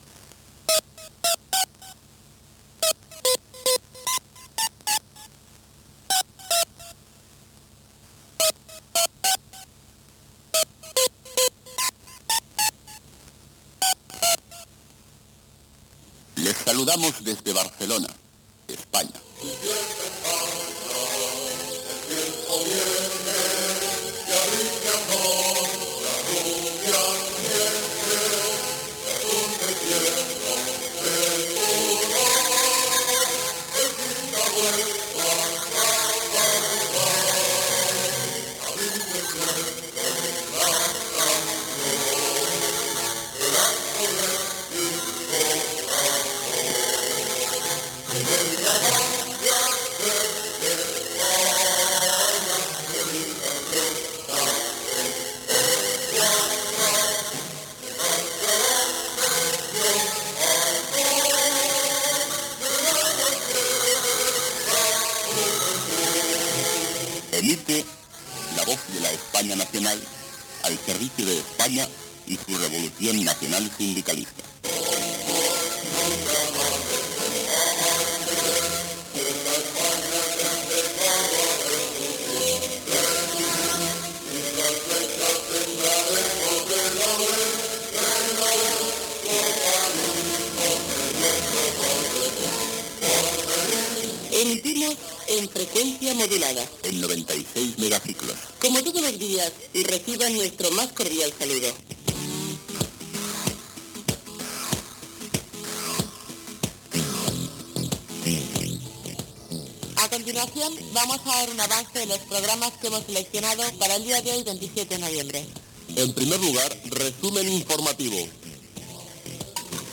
Sintonia, inici d'emissió, continguts del dia, sumari informatiu, identificació, jota, demanda de llibertat per als militars Tejero i Ynestrillas, identificació i demanda de controls de recepció
FM